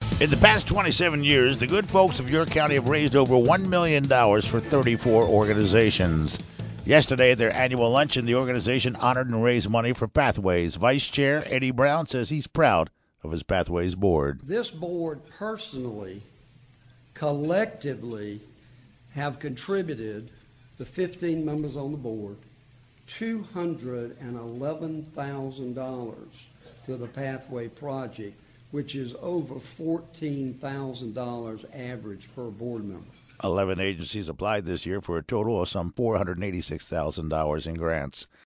AUDIO: Pathways honored by Good Folks of York Co. at annual luncheon